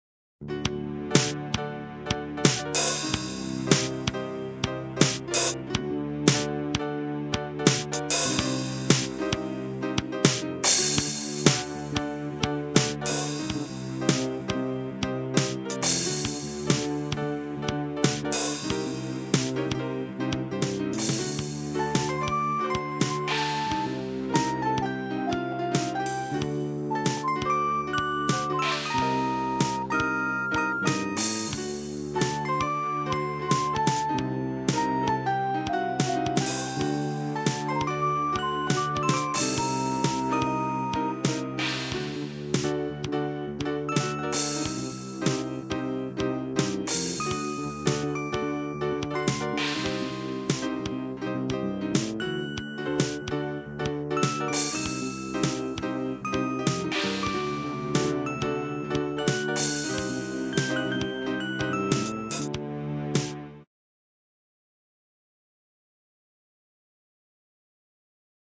Flute With Drums And Picolo
flutewithdrumsandpiccolo_0.ogg